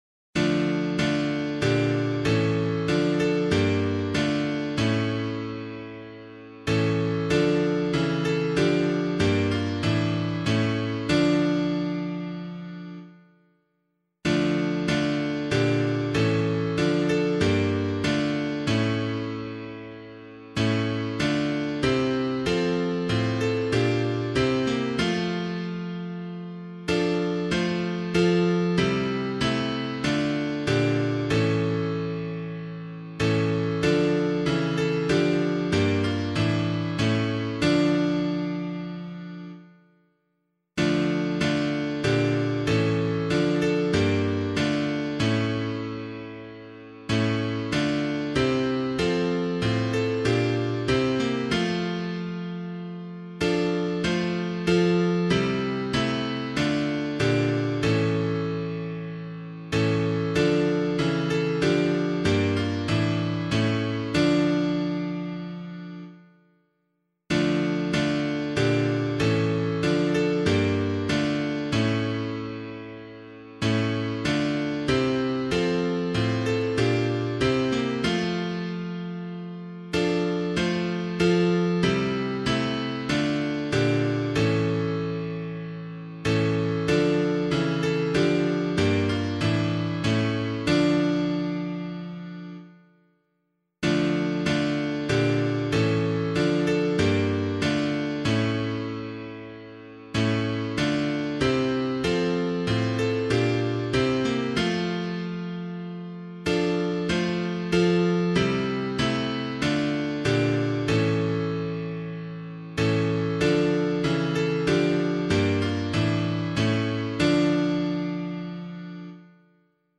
Public domain hymn suitable for Catholic liturgy.
Hear O Thou Bounteous Maker Hear [anonymous - BABYLON'S STREAMS] - piano.mp3